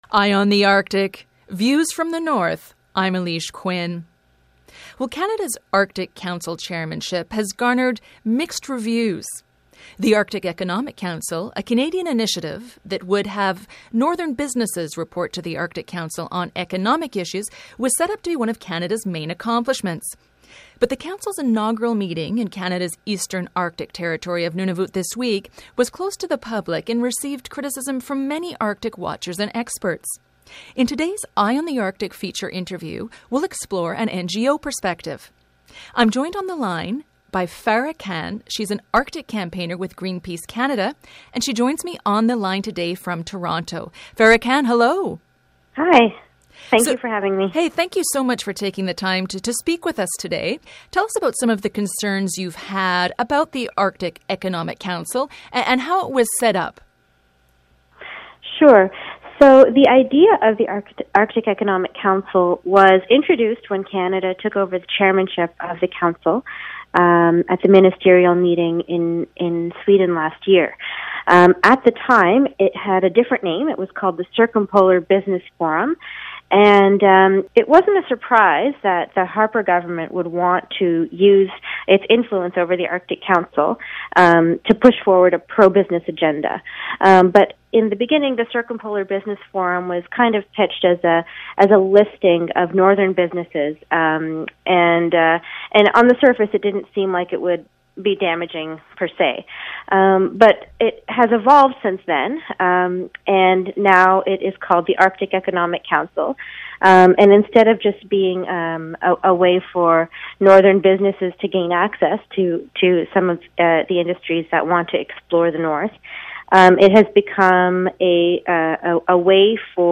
Feature Interview: Arctic Economic Council raises environmental concerns